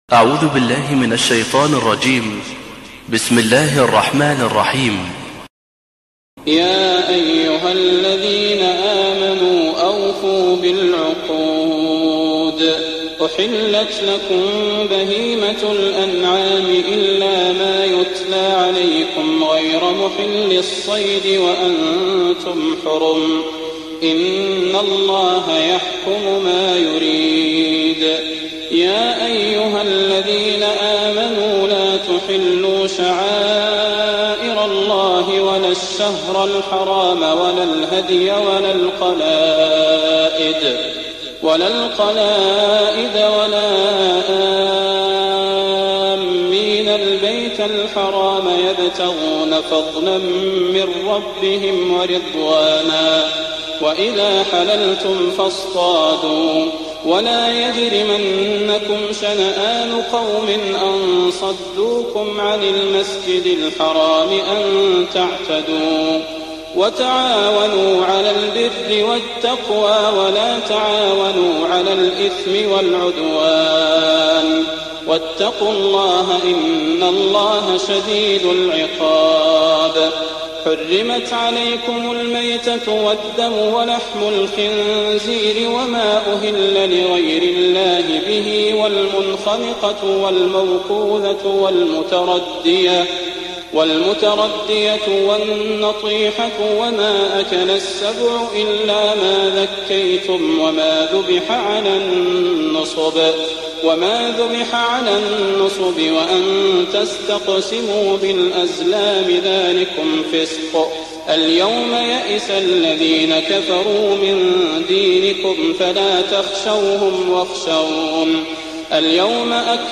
تهجد ليلة 25 رمضان 1419هـ من سورتي النساء (148-176) و المائدة (1-50) Tahajjud 25th night Ramadan 1419H from Surah An-Nisaa and AlMa'idah > تراويح الحرم النبوي عام 1419 🕌 > التراويح - تلاوات الحرمين